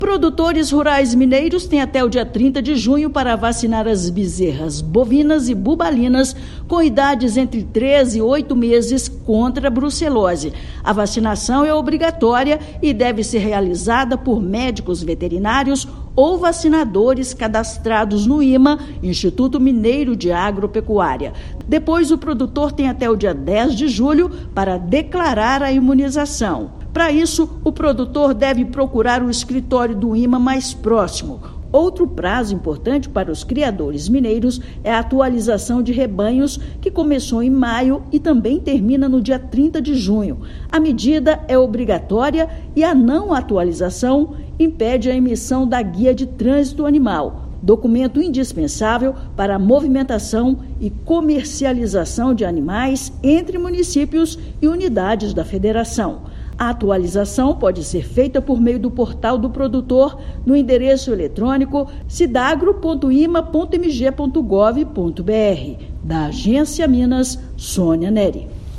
Bezerras bovinas e bubalinas entre 3 e 8 meses devem ser imunizadas até o final do mês; declaração da imunização ao IMA deve ser feita até 10/7. Ouça matéria de rádio.